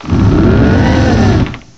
cry_not_trevenant.aif